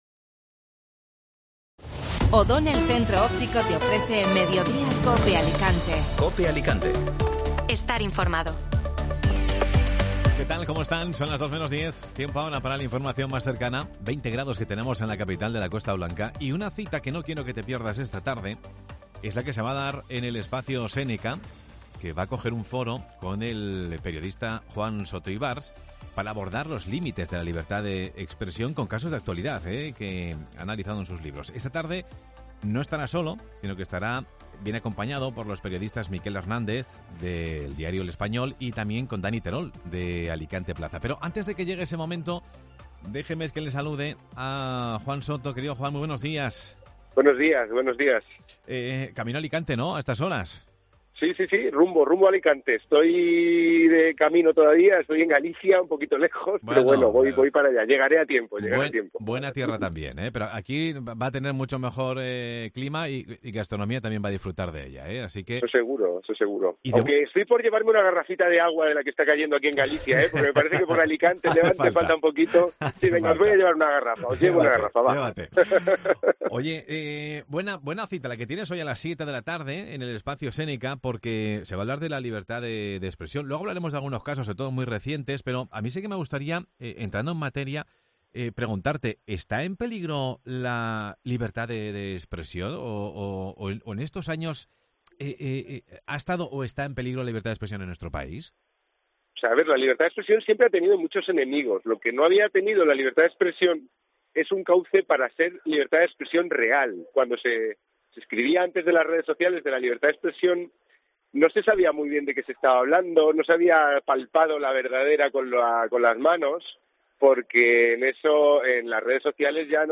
Escucha la entrevista en Mediodía COPE Alicante